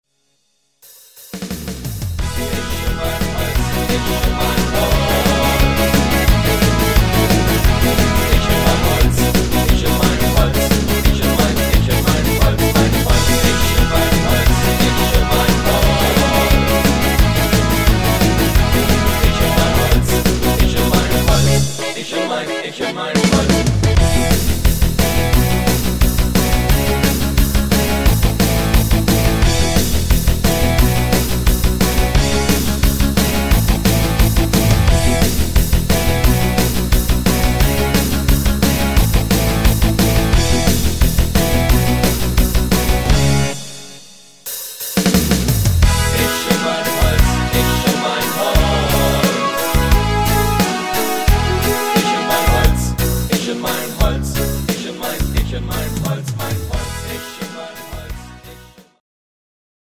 Rock Version